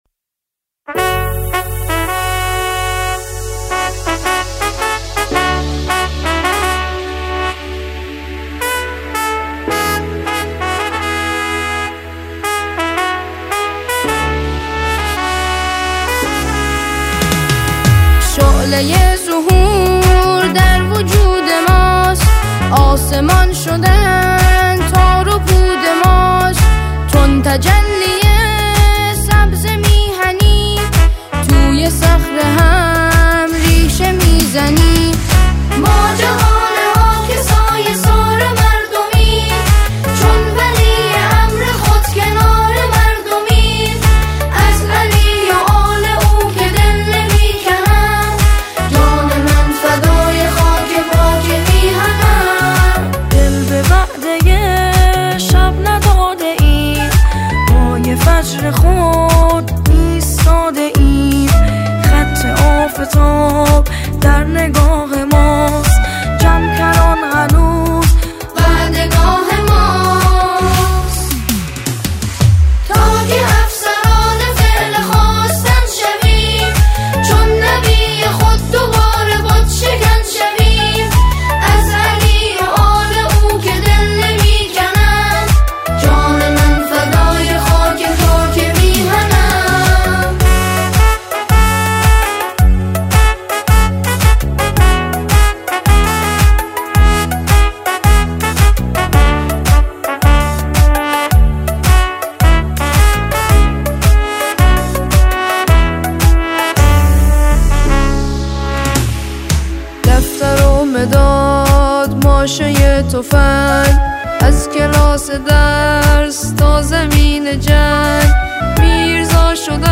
سرودهای بسیج